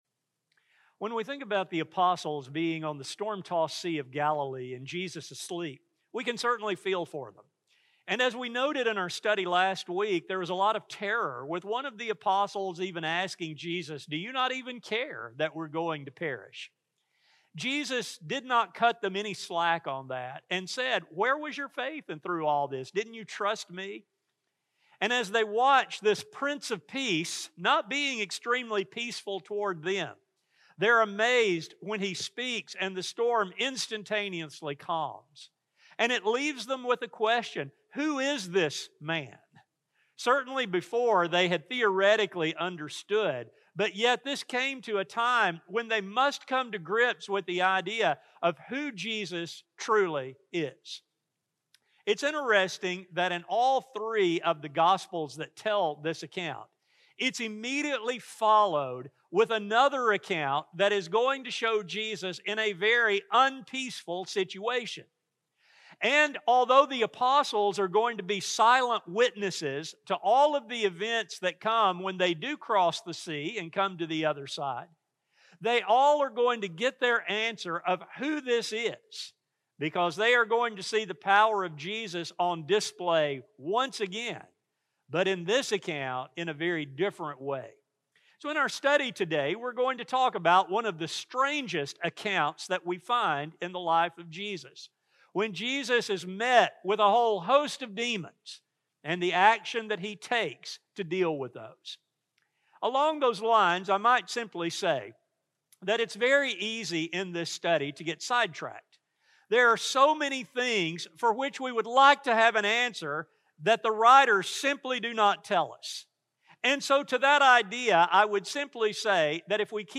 This sermon focuses on Jesus' reaction to the apostles' lack of faith, while making points concerning our own faith in Him.